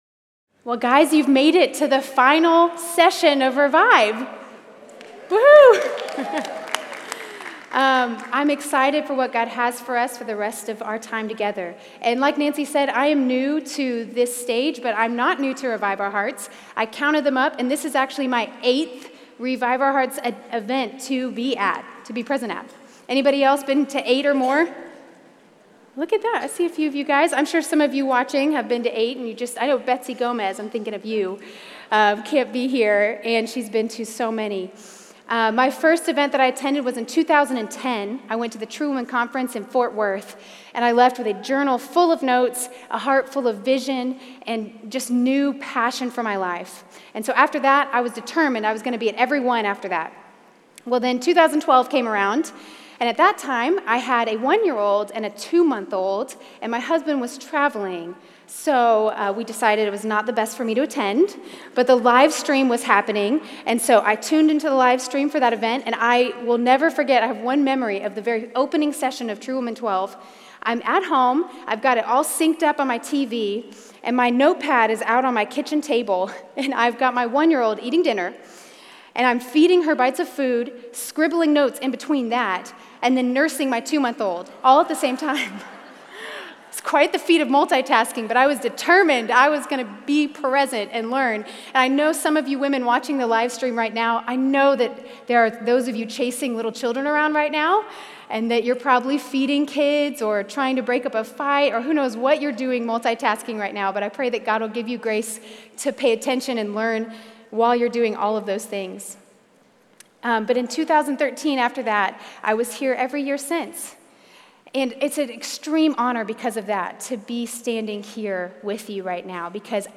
Life in the Spirit | Revive '19 | Events | Revive Our Hearts